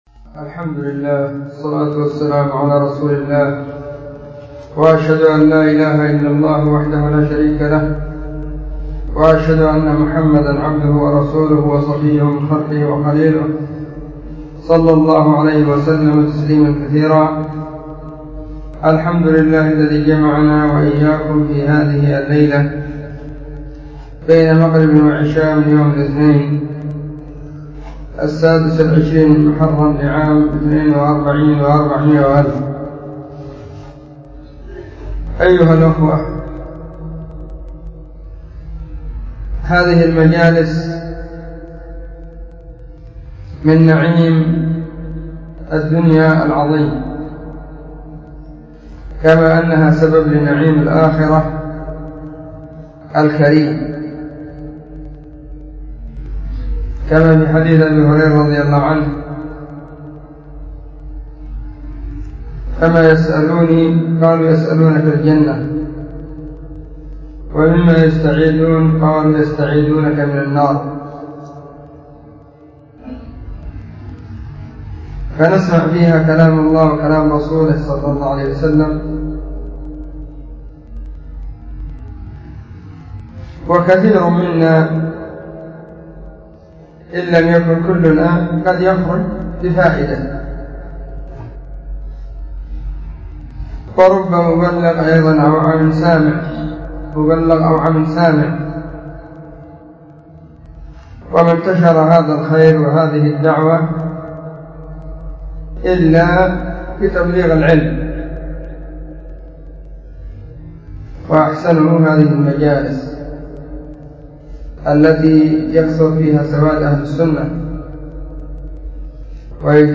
محاضرة بعنوان طلب عون الله
📢 مسجد الصحابة – بالغيضة – المهرة، اليمن حرسها الله